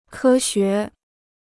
科学 (kē xué): science; scientific knowledge.